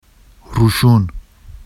[ruʃun] adj bright, light, on